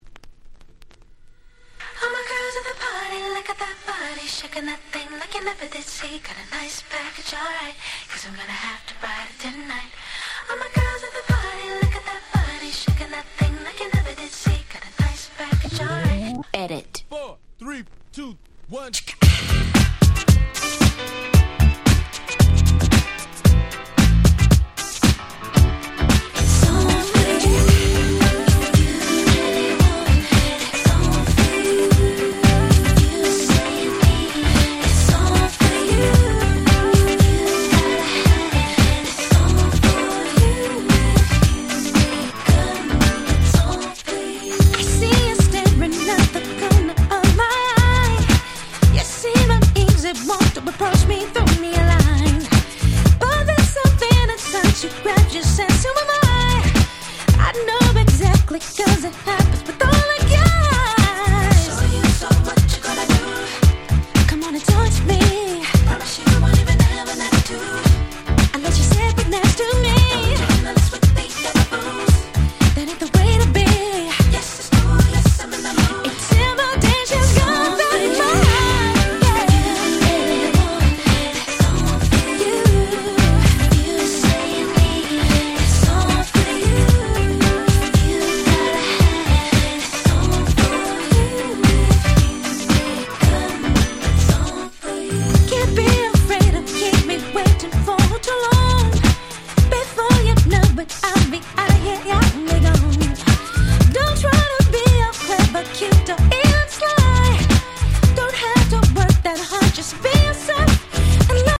01' 特大ヒットR&B！！！！！